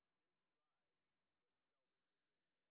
sp06_street_snr0.wav